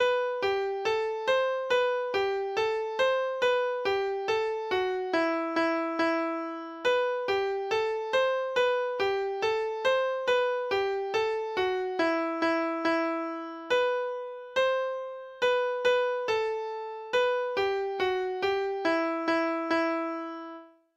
Pattedyrene, Sanglek - last ned nota Lytt til data-generert lydfil Omkved ved Ingeborg Refling Hagen.